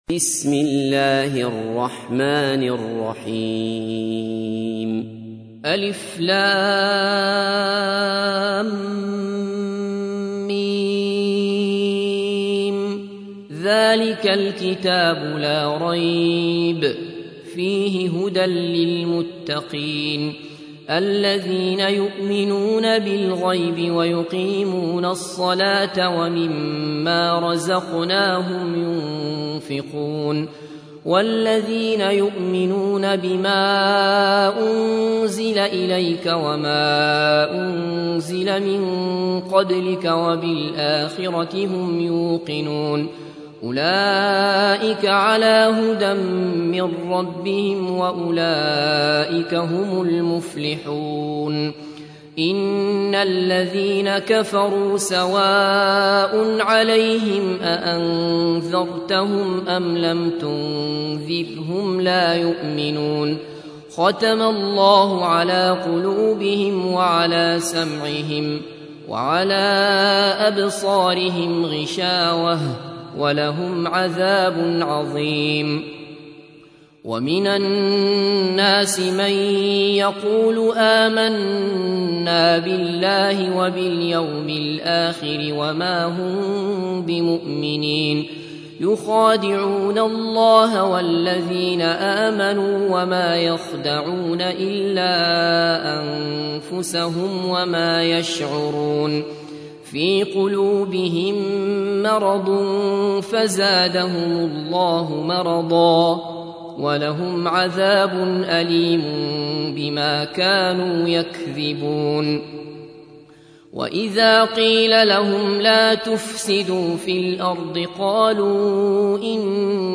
تحميل : 2. سورة البقرة / القارئ عبد الله بصفر / القرآن الكريم / موقع يا حسين